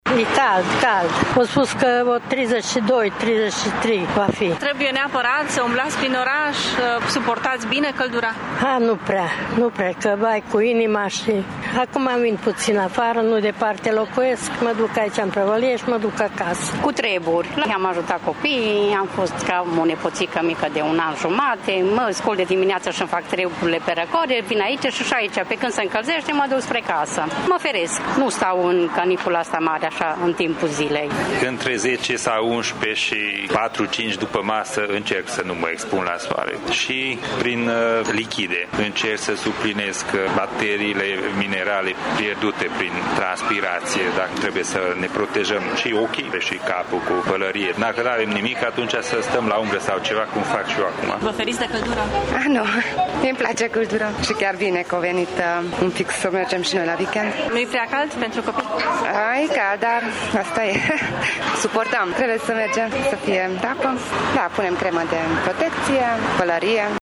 voxuri-canicula.mp3